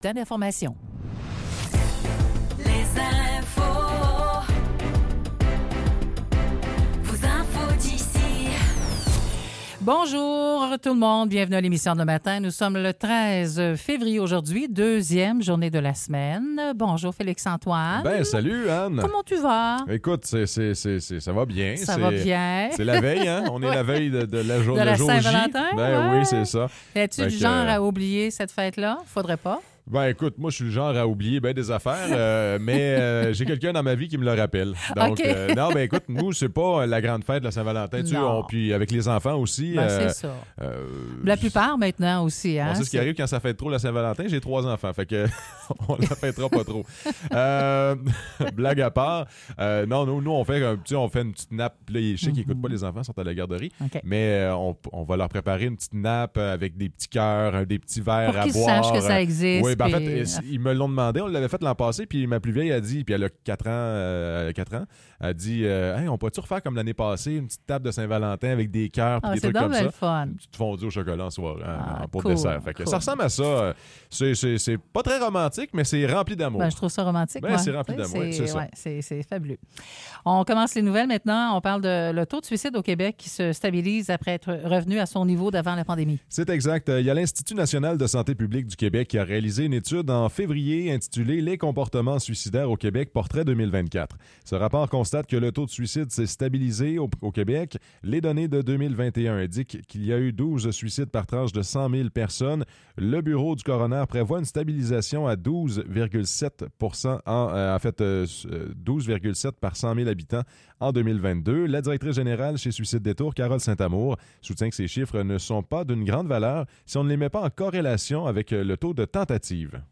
Nouvelles locales - 13 février 2024 - 9 h